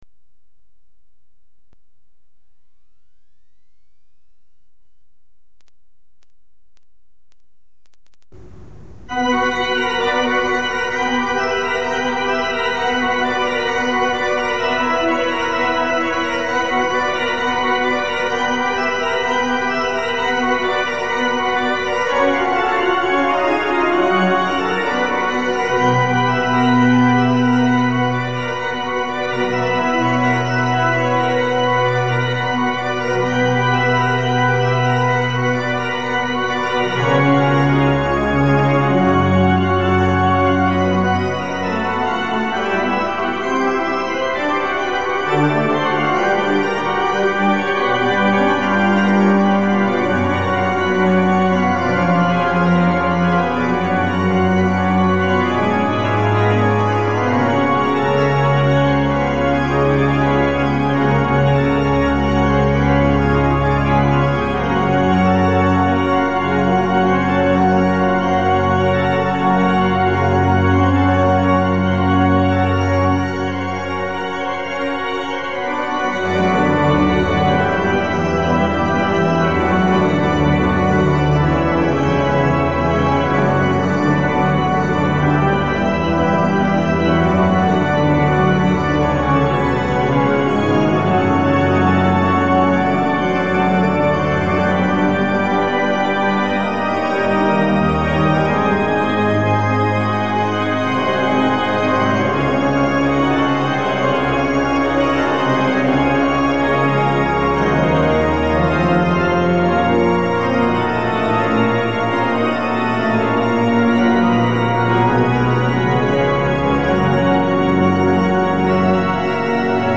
Het orgel van de Elisabethkerk in Breslau
breslau_elisabethkerk.mp3